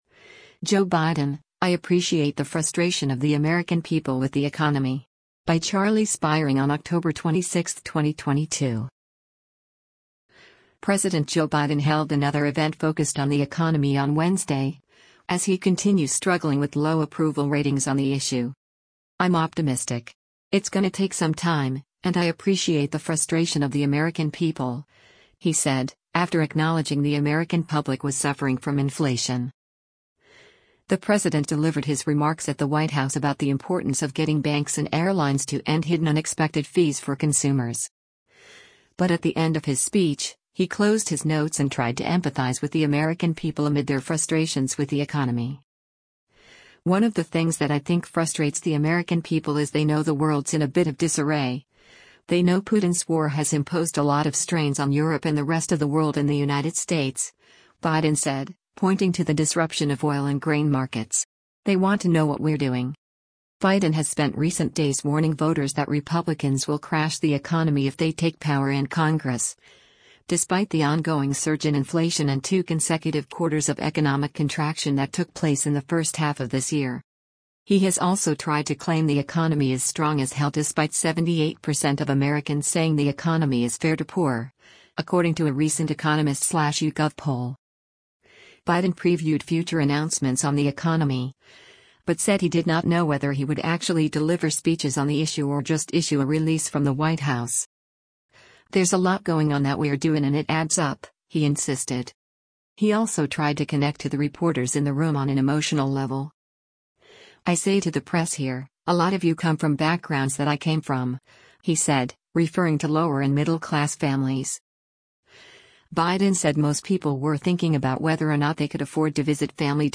The president delivered his remarks at the White House about the importance of getting banks and airlines to end hidden unexpected fees for consumers.